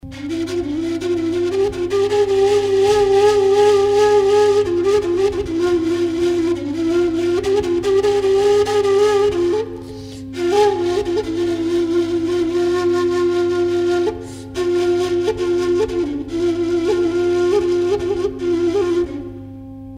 Тональность: С
Блул, он же сринг - традиционный армянский инструмент, являющийся разновидностью диагональной флейты.Инструмент имеет хроматический ряд.
Диапазон: 2,5 октавы. Материал: абрикос